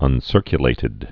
(ŭn-sûrkyə-lātĭd)